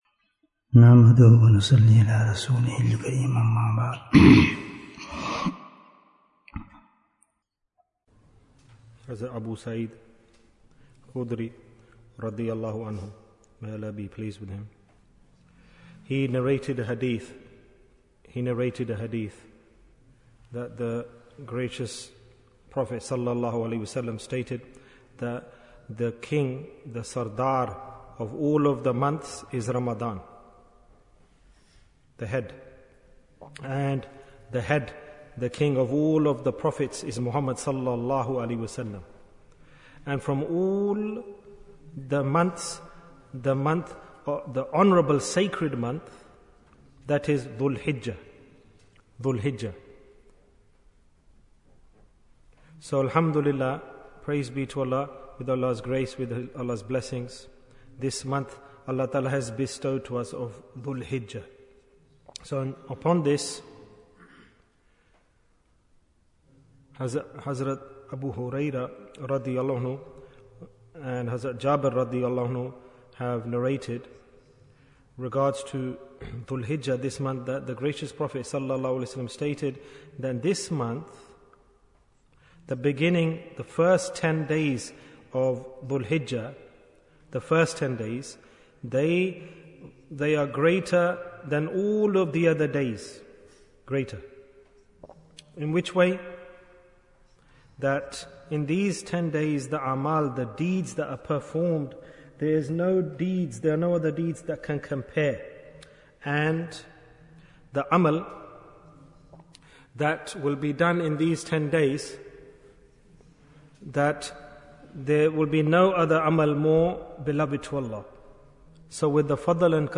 Deed for the Ten Days of Dhul Hijjah Bayan, 13 minutes18th June, 2023